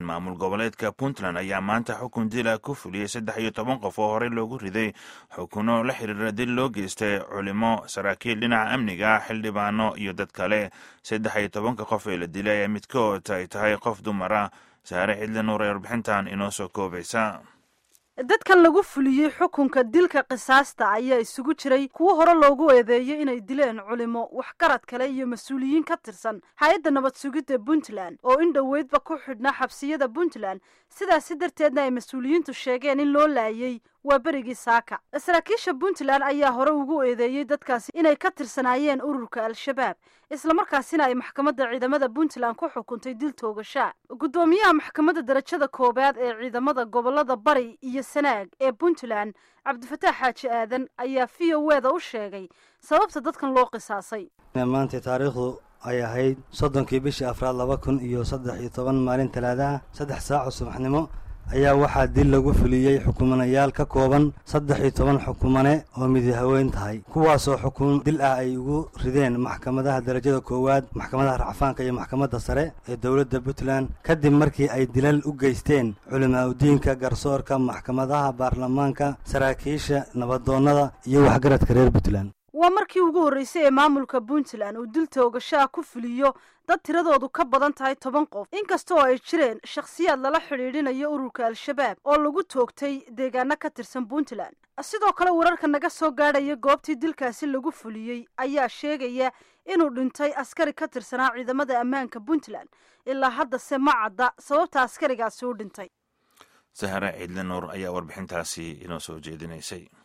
Warbixinta Toogashada Boosaaso